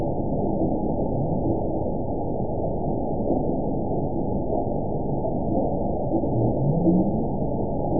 event 922662 date 02/27/25 time 04:28:46 GMT (3 months, 3 weeks ago) score 9.16 location TSS-AB02 detected by nrw target species NRW annotations +NRW Spectrogram: Frequency (kHz) vs. Time (s) audio not available .wav